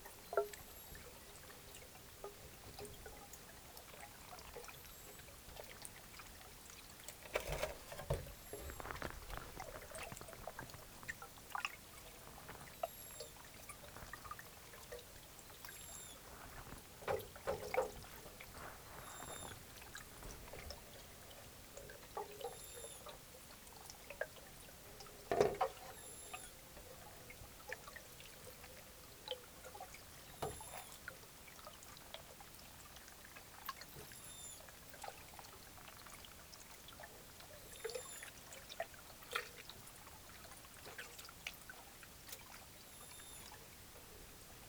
Sounds recorded in the Peruvian Amazon.
Trichechus inunguis whistle series spectrogram Spectrogram of a Trichechus inunguis call series recorded on July 7, 2012.
Trichechus inunguis am6 Z11 whistle series_sel.14.wav